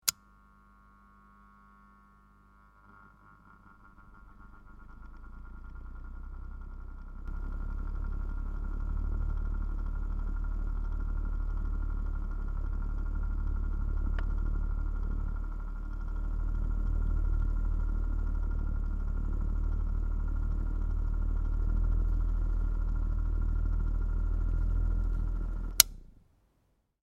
Philips HA2728 fan